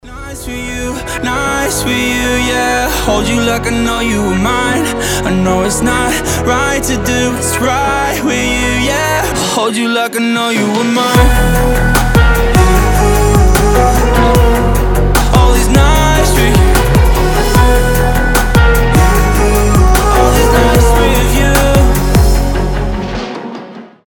• Качество: 320, Stereo
красивый мужской голос
мелодичные
future bass